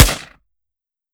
5.56 M4 Rifle - Gunshot B 003.wav